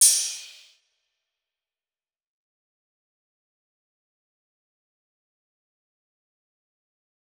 Crashes & Cymbals
DMV3_Crash 5.wav